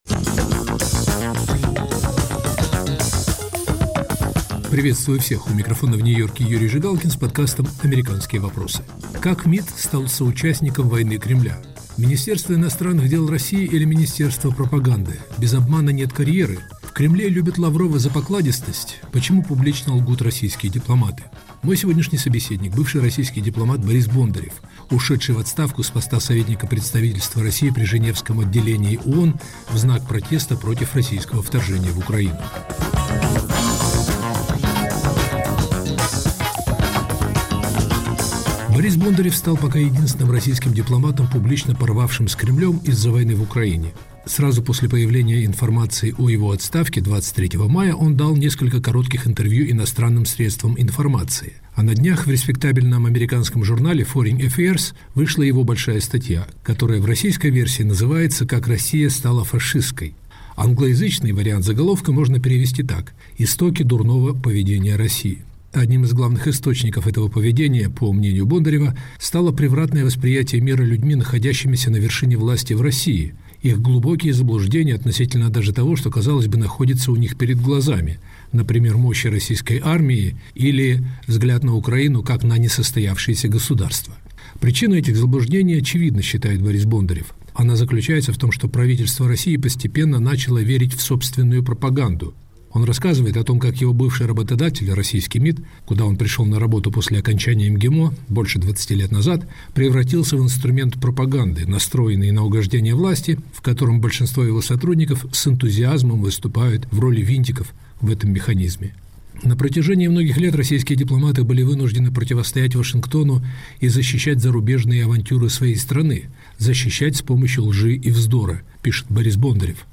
Почему публично лгут российские дипломаты? Наш собеседник – бывший высокопоставленный дипломат МИД РФ Борис Бондарев, ушедший в отставку в знак протеста против российского вторжения в Украину.